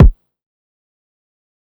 (SXJ) Kick (2).wav